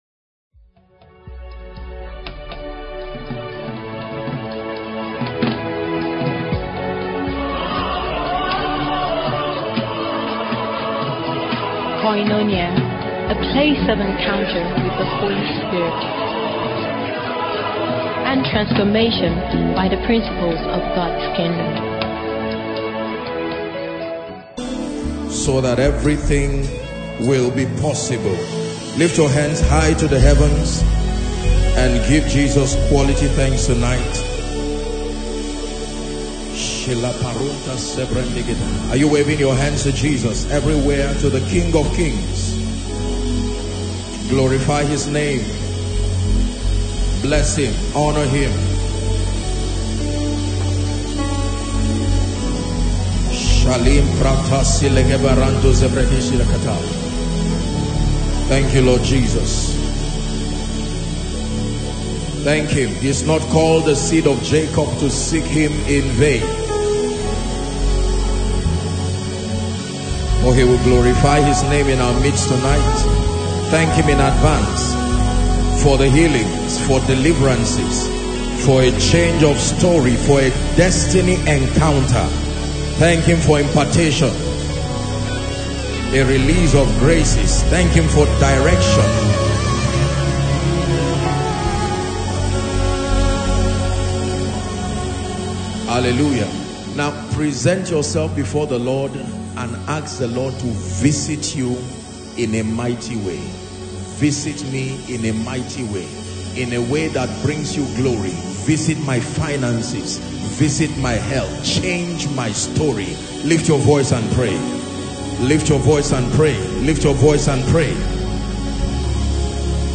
The anticipation was evident as worshippers filled the hall, ready for an experience that would redefine their faith and destinies. For many, this service was more than an event it was their moment of breakthrough.